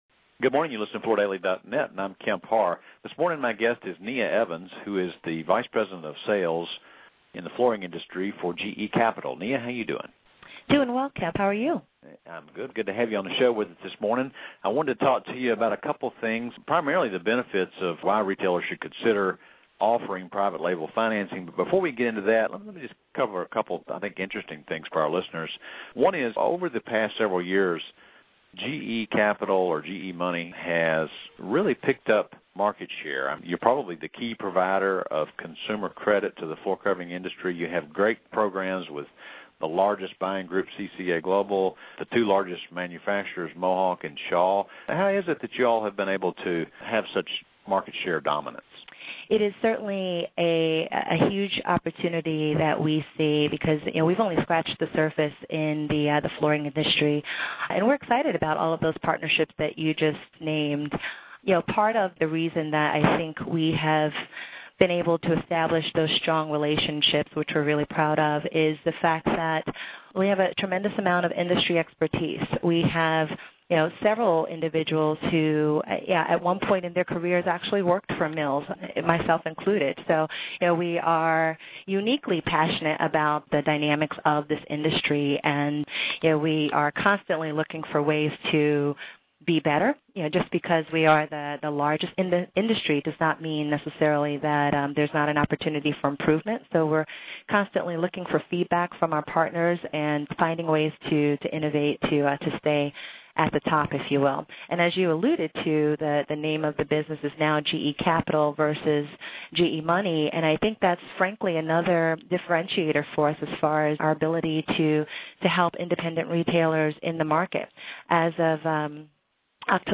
Listen to the interview to find out how private label financing can lead to higher average ticket sales and help increase close rates, among many other benefits. Also listen to find out why GE Capital has been able to increase its footprint in the flooring industry in recent years.